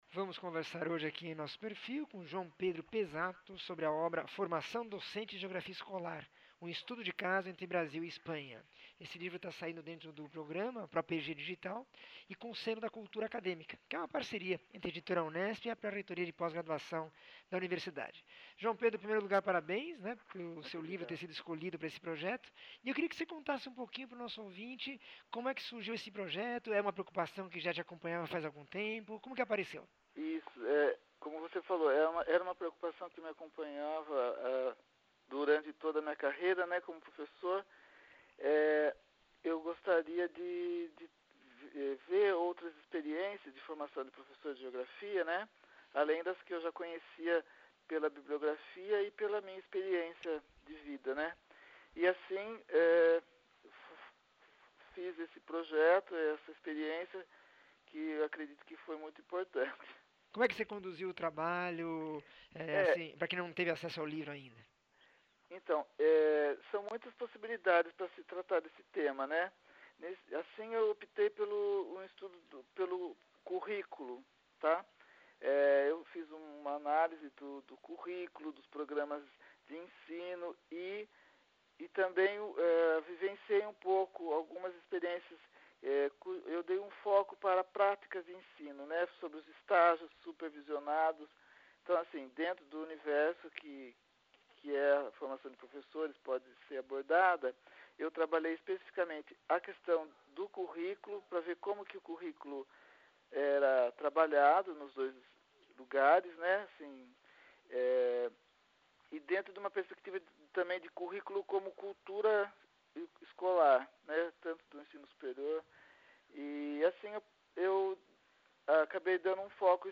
entrevista 1732